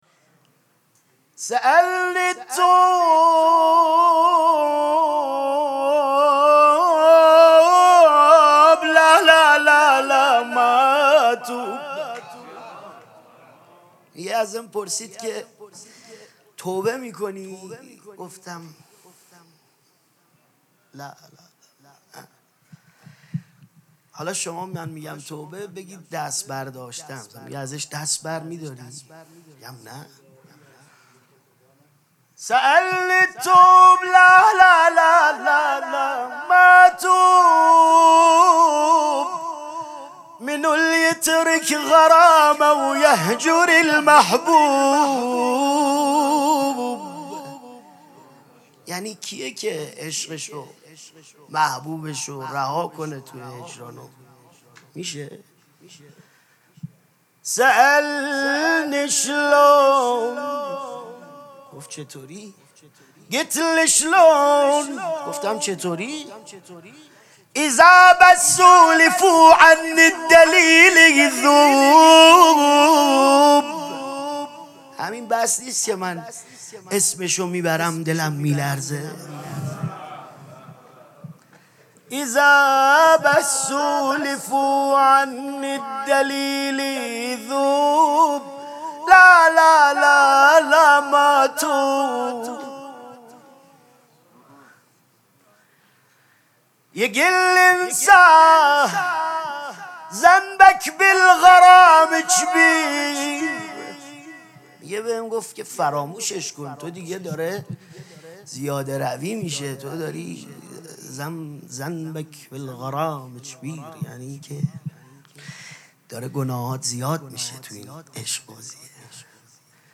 ابتهال خوانی
مراسم جشن شام ولادت امام حسن مجتبی(ع)
حسینیه ریحانه الحسین سلام الله علیها